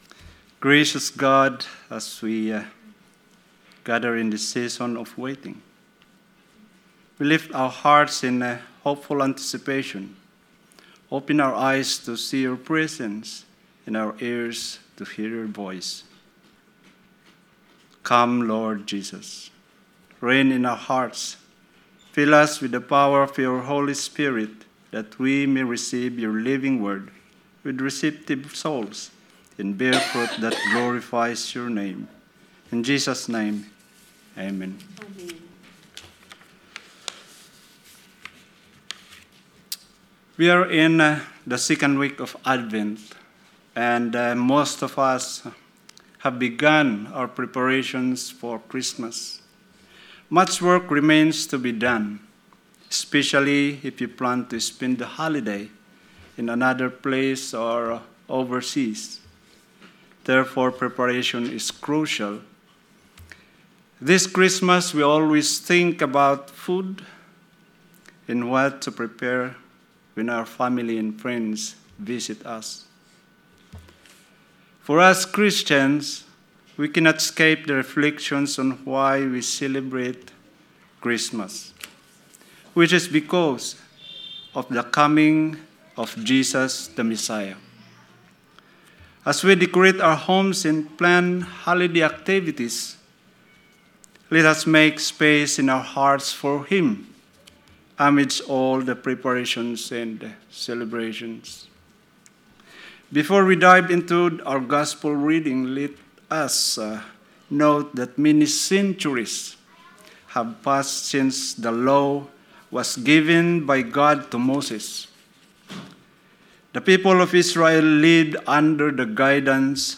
Sermon 7th December 2025 – A Lighthouse to the community